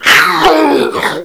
assets/nx/nzportable/nzp/sounds/zombie/d7.wav at 272a0cf914da780f286dac26ff04e1fa21beba33